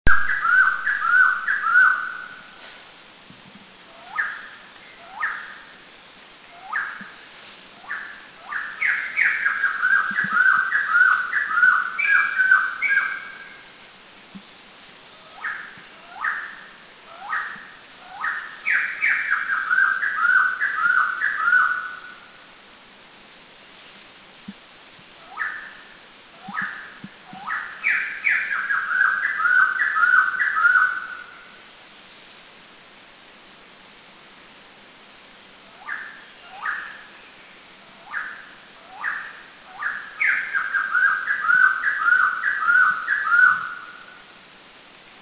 White-crested Laughingthrush 2
White-crestedLT2.mp3